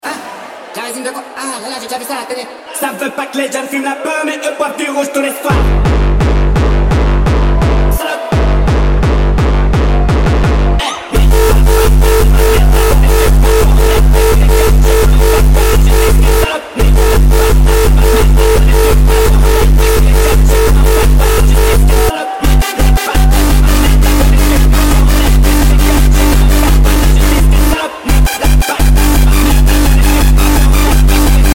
uptempo